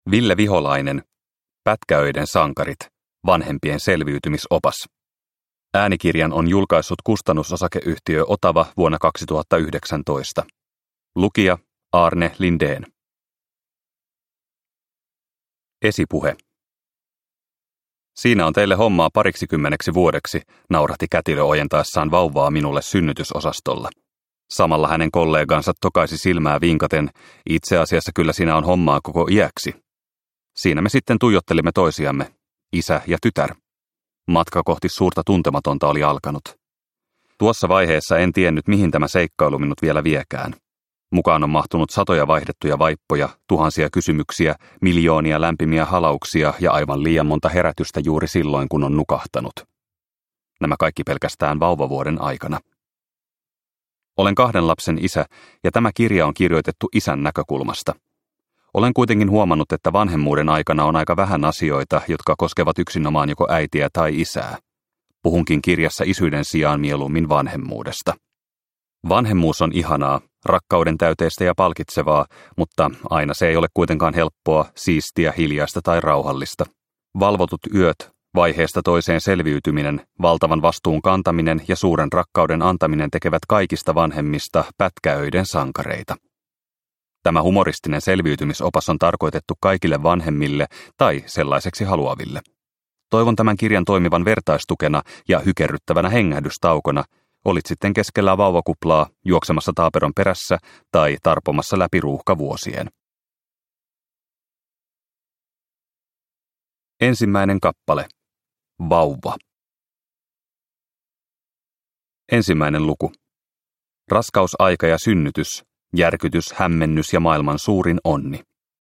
Pätkäöiden sankarit – Ljudbok – Laddas ner